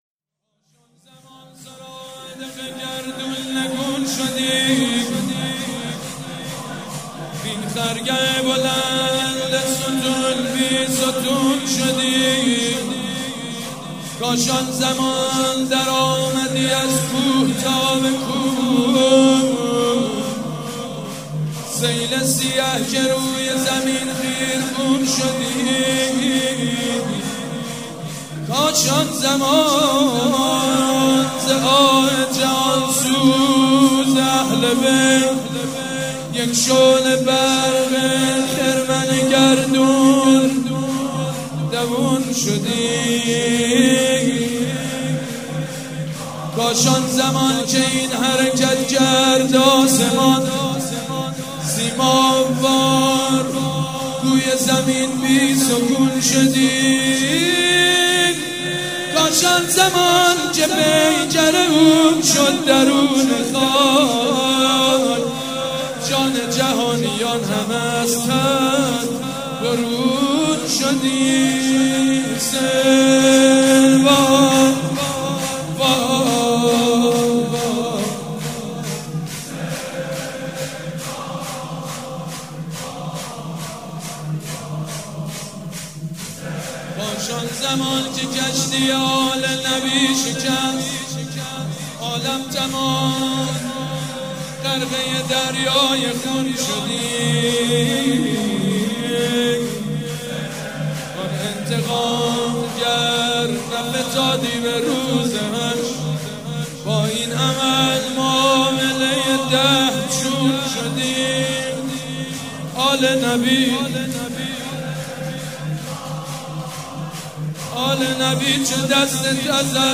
شب سوم محرم - به نام نامیِ حضرت رقیه(س)
محرم 95 | شور | حسین وای
سید مجید بنی فاطمه